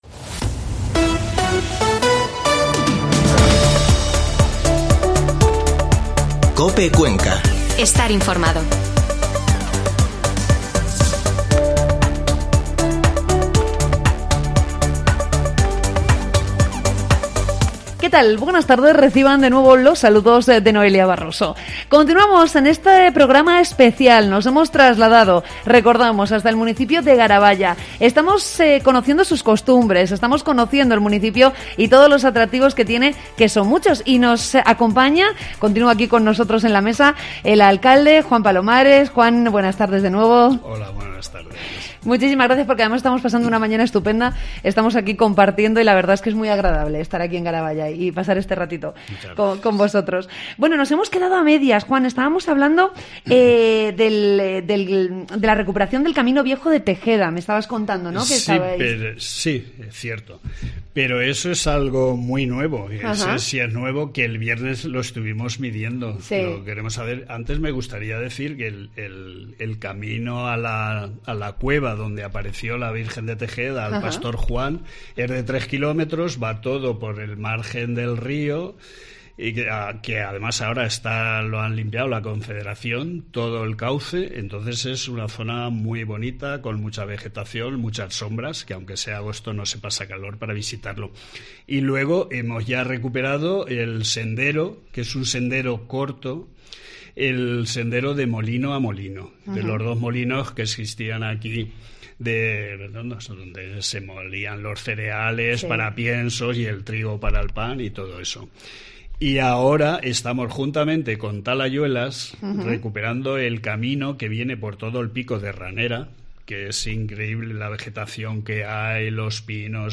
Mediodía COPE Cuenca desde Garaballa